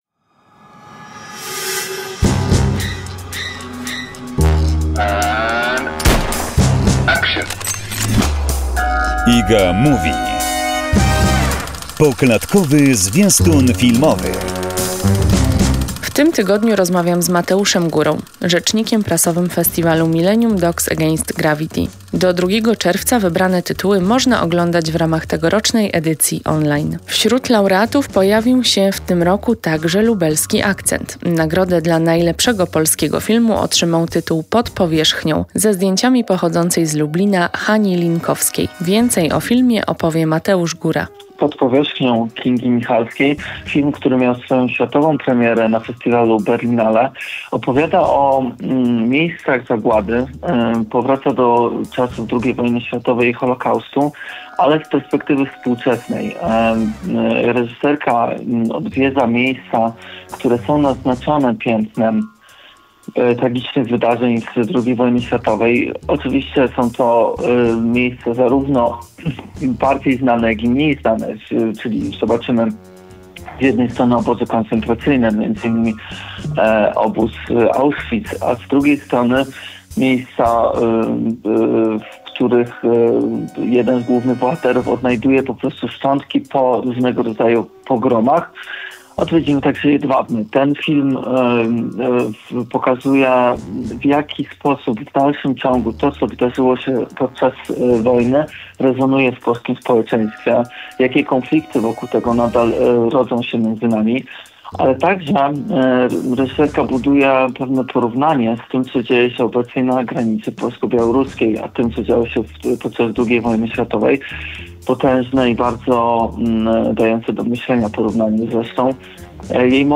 Trzecia część rozmowy